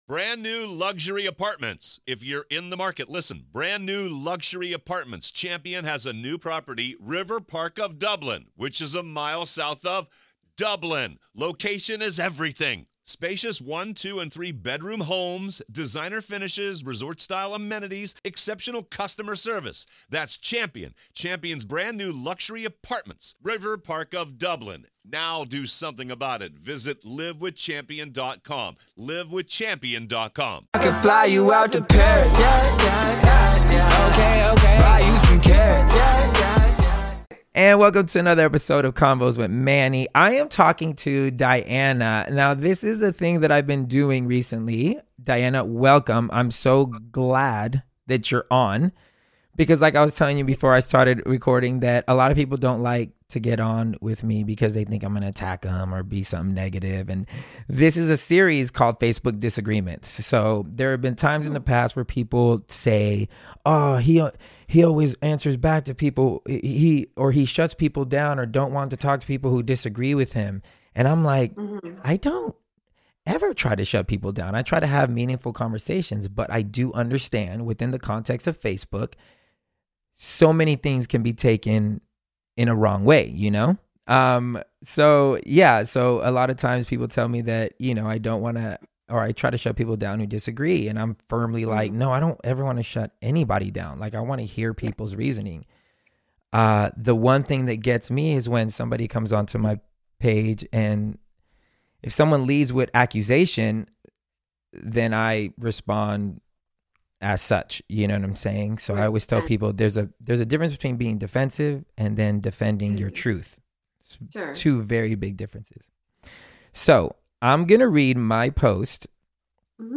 We dive into that conversation